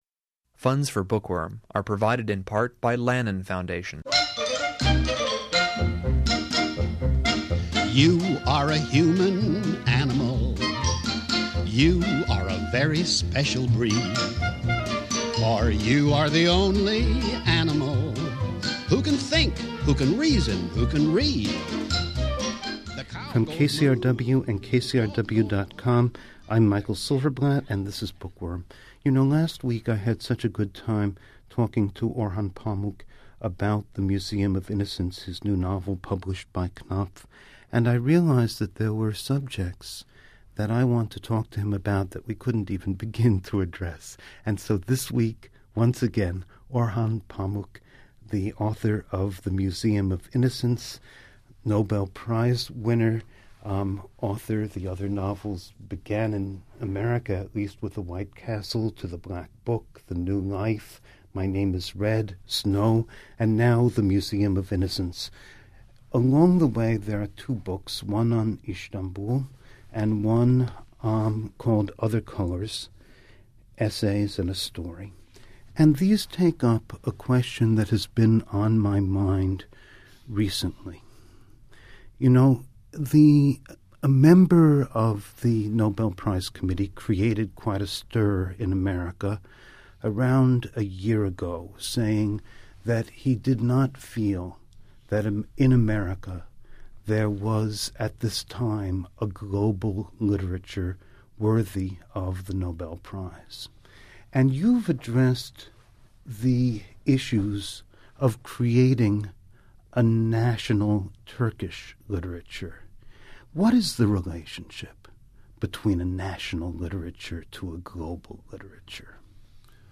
Our conversation centers on the problem of national versus global literatures.